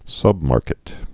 (sŭbmärkĭt)